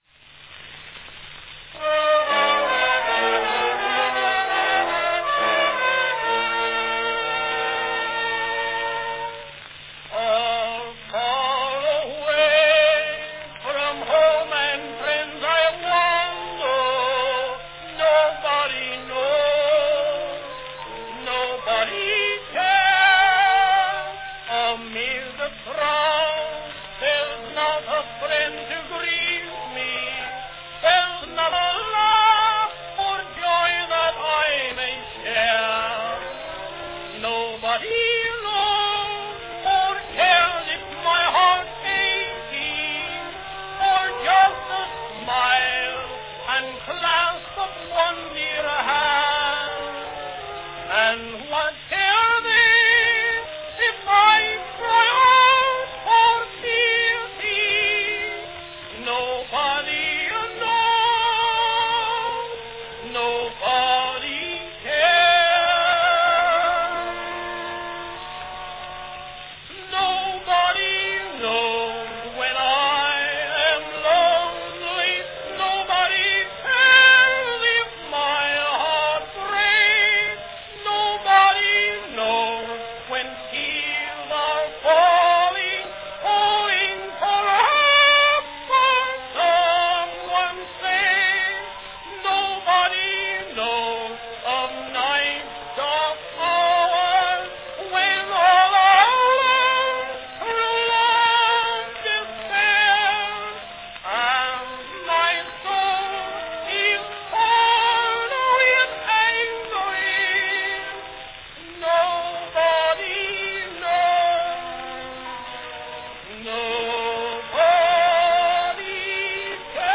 Sometimes only a true-blue dismal, downcast and downer of a song will do.
Category Counter-tenor
Announcement None
countertenor voice (singing between the tenor and soprano ranges)
Few recordings have been rendered with such striking combination of sentiment and singer as this.